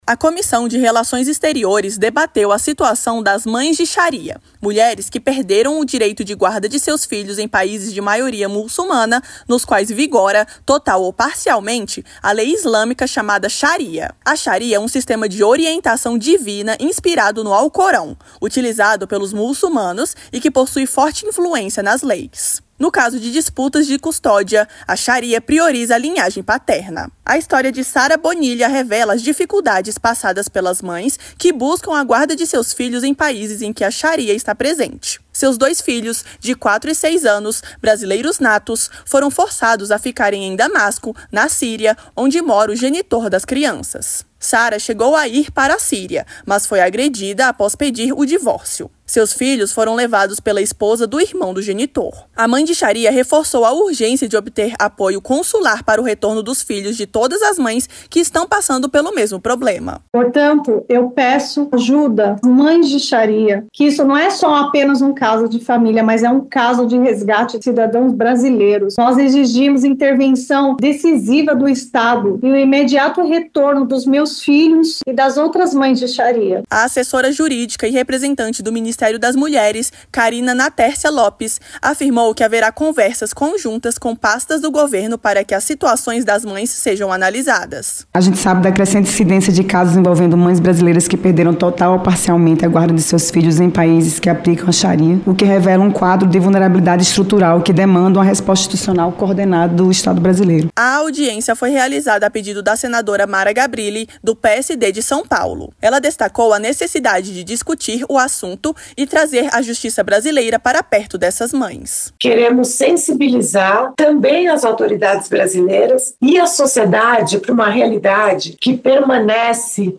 Audiência Pública Interativa